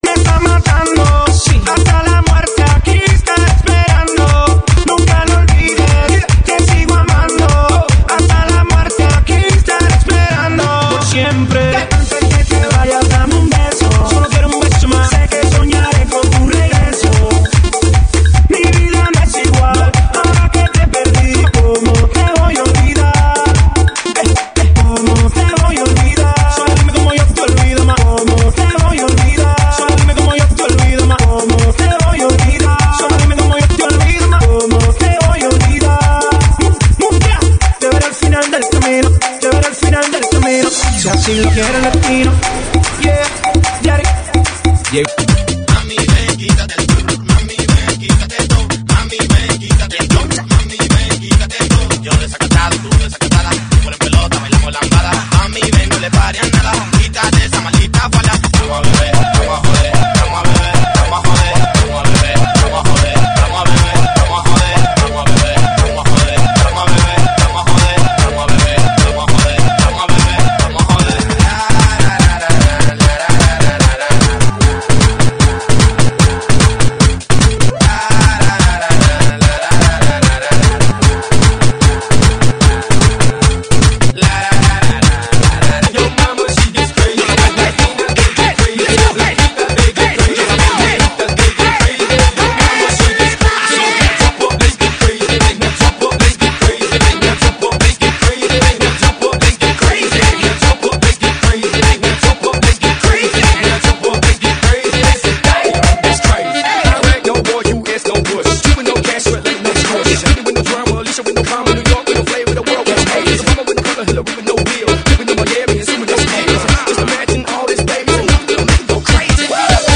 GENERO: LATINO – REMIX – HOUSE